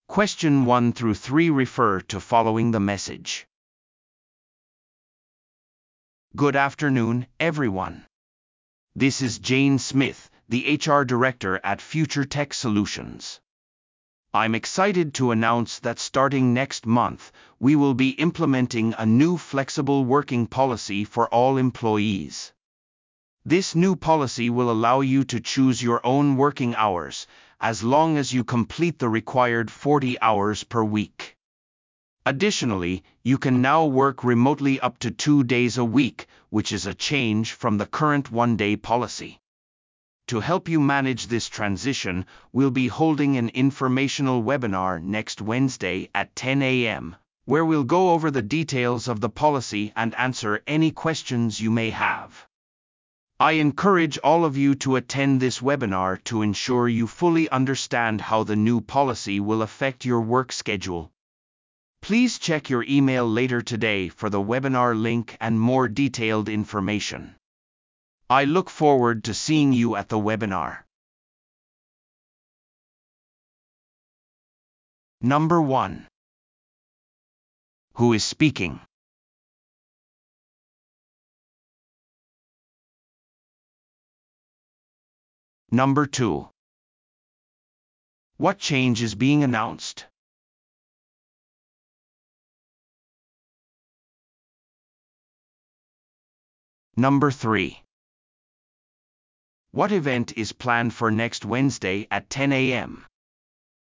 PART4は一人語り形式のリスニング問題です。